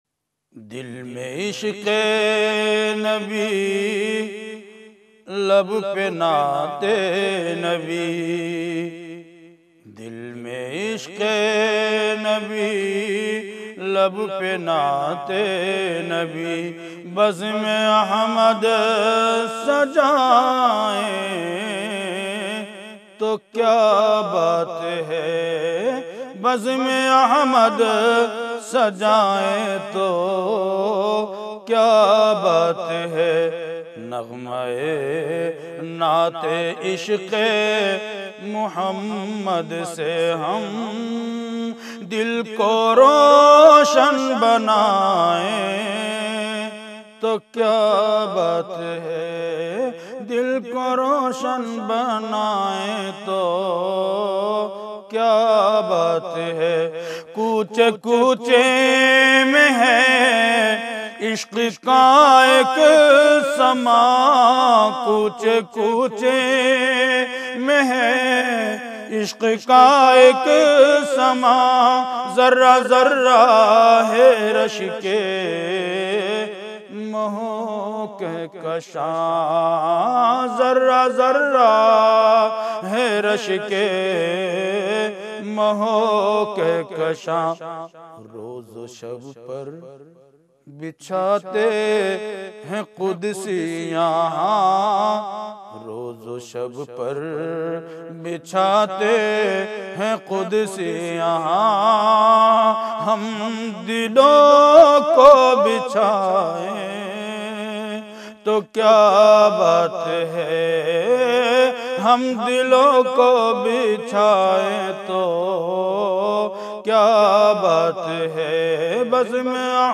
Beautiful Naat